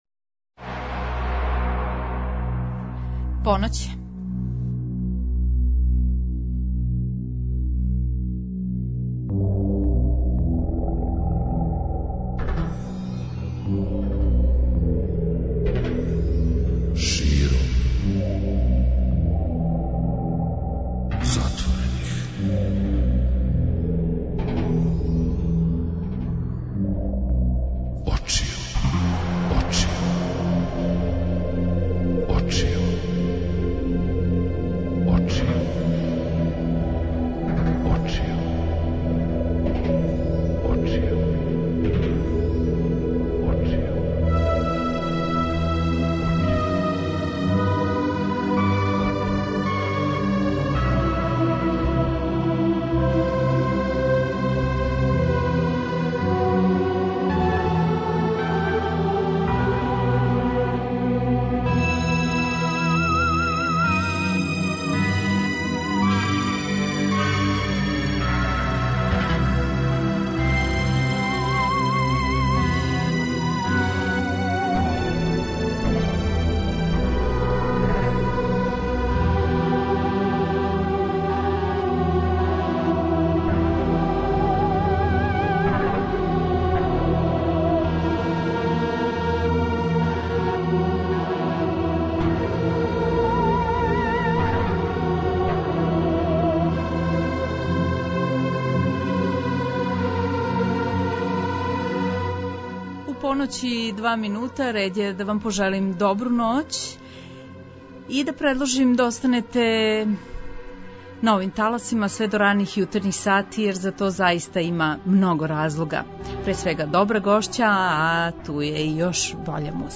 Такође, слушаоци ће имати прилике да чују премијерно и њену нову композицију.